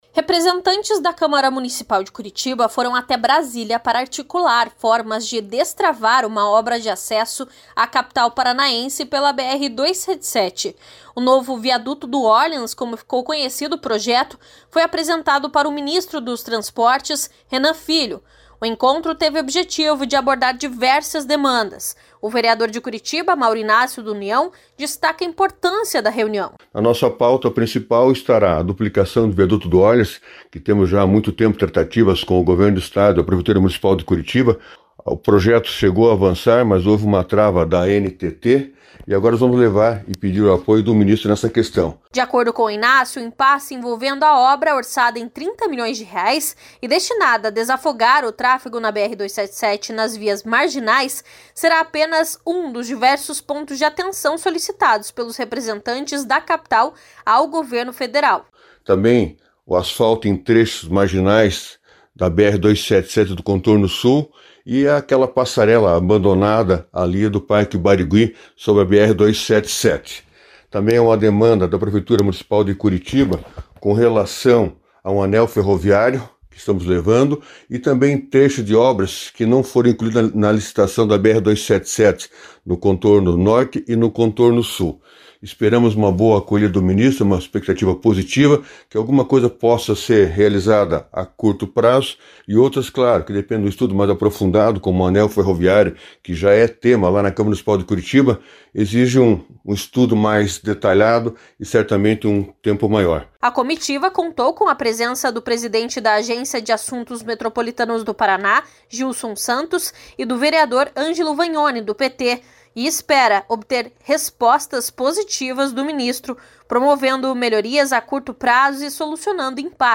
O vereador de Curitiba, Mauro Ignácio (União), destaca a importância da reunião.